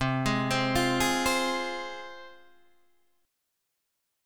C Suspended 4th Sharp 5th